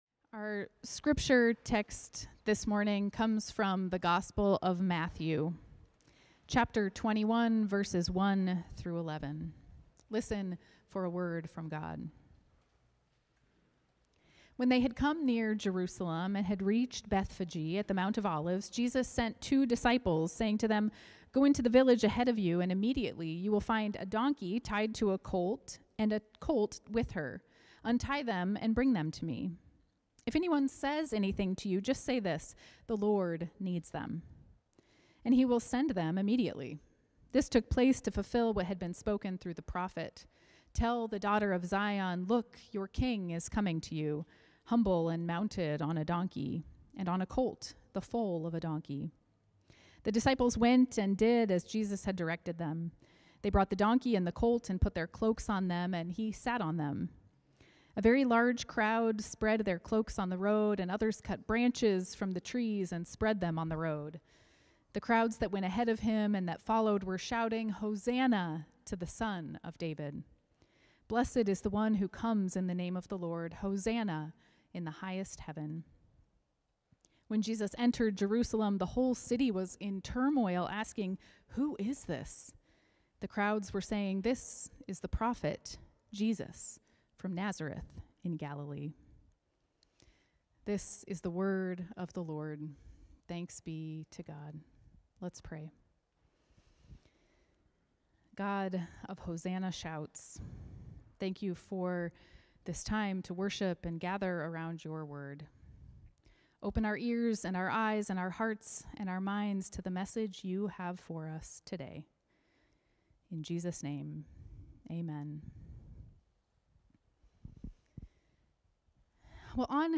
Sermons | Fort Street Presbyterian Church